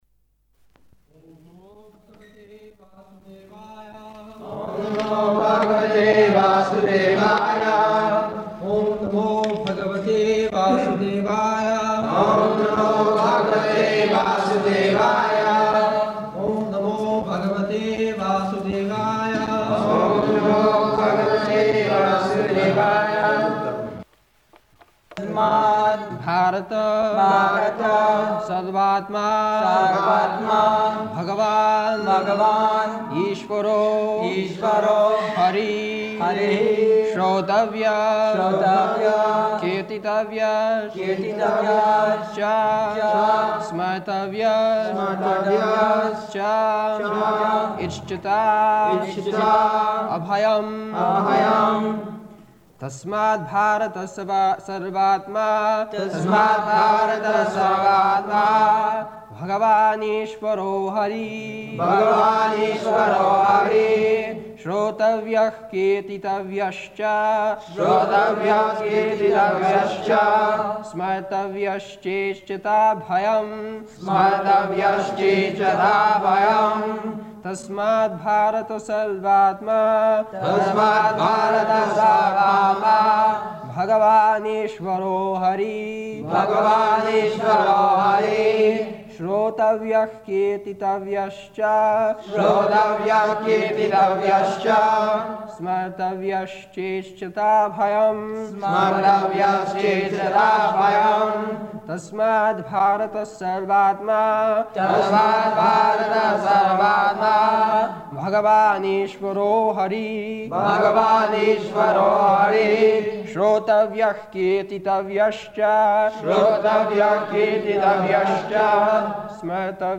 November 8th 1973 Location: Delhi Audio file
[Prabhupāda and devotees repeat]